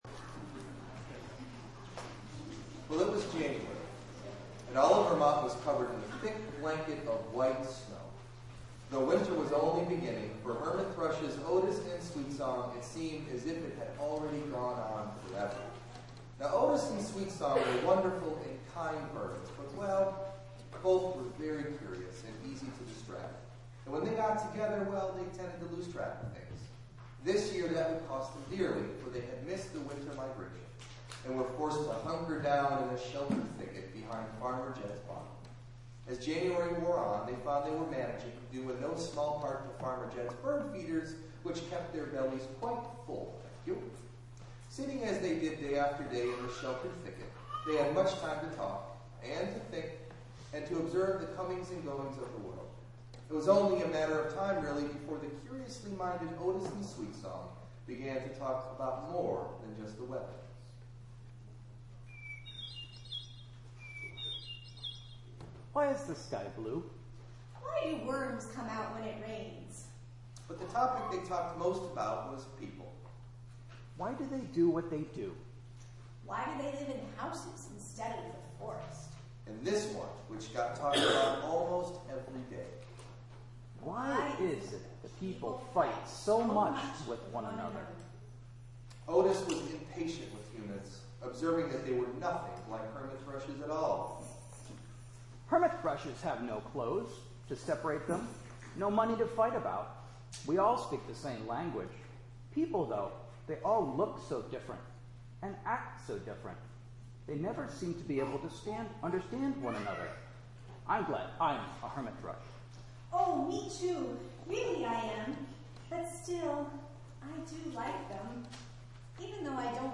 The audio this week is an edited version of a “Family Service” we called “From Babel to the Table.” Much of the service has been included, including hymns, prayers, meditation, etc. which all help to the tell the story.